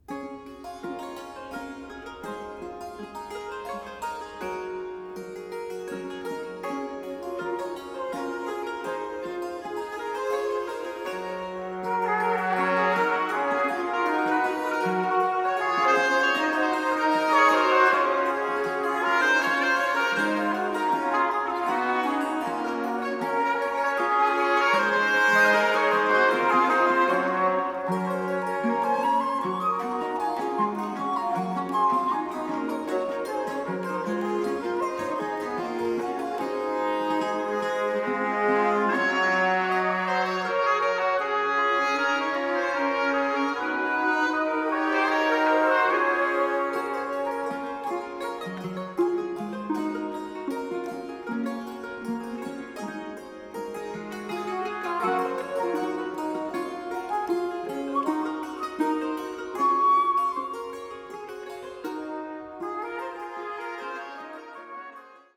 met tot twintig zangers en instrumentalisten